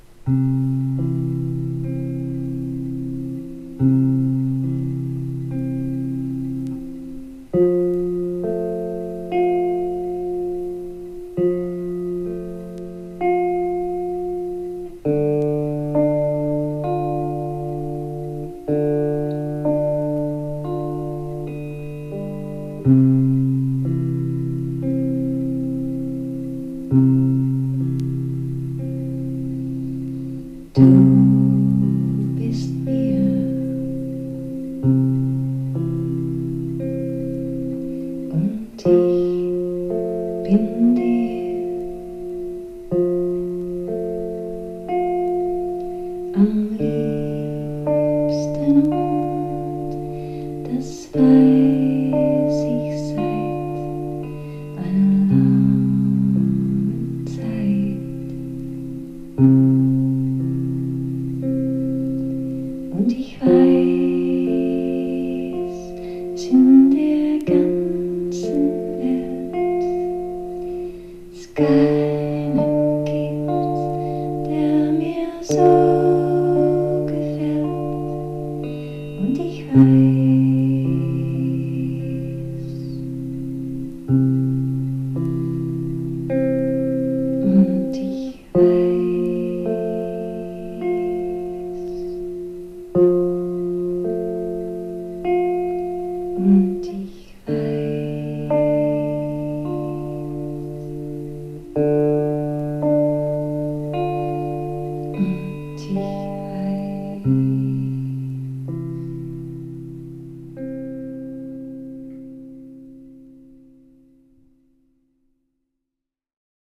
3. > PSYCHEDELIC/PROGRESSIVE/JAZZ ROCK
アンビエンスの効いた空間に、ミニマルに漂い引き込んでいく、ボーカルとギターが癖になる全8曲！